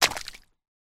waterFootstep01.wav